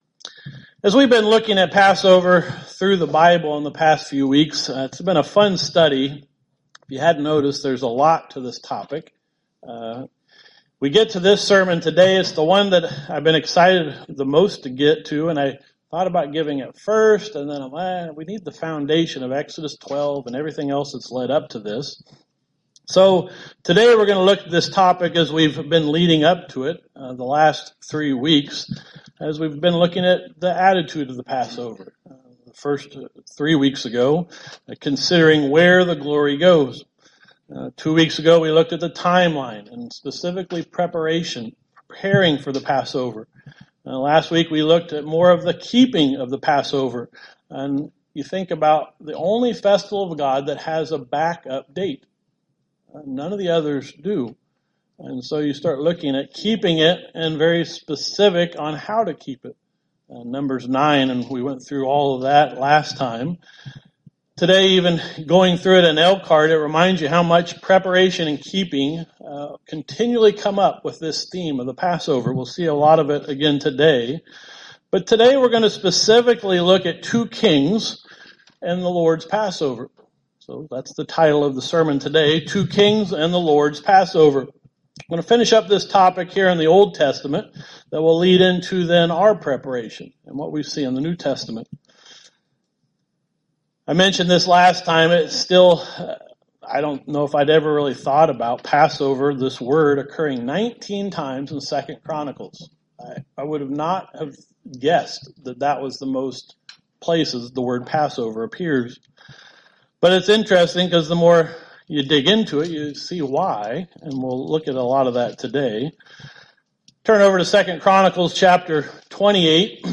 Given in Elkhart, IN Northwest Indiana